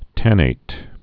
(tănāt)